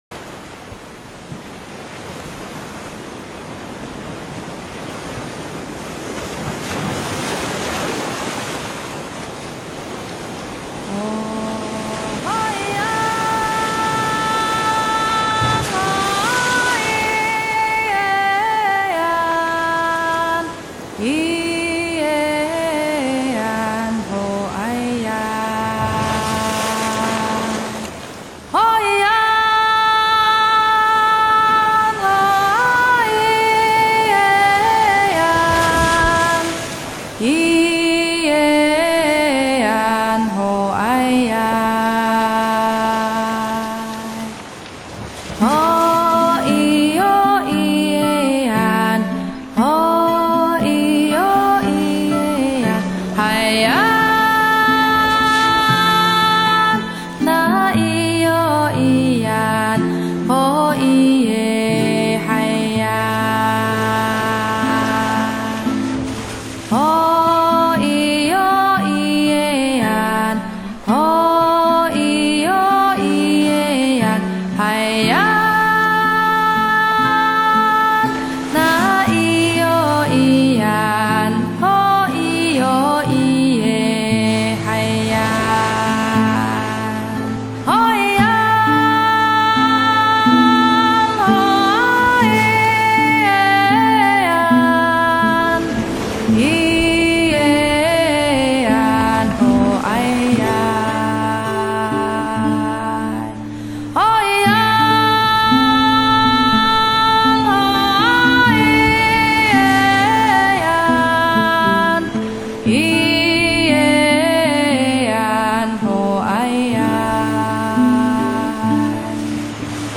New Age、轻爵士、佛朗明哥吉他、古典钢琴小品、民谣风…10首音乐10种风格，实在好听的创作曲献给天下知音人。
独唱
海洋民族的歌声搭着潮浪的节奏，一致得让人感动。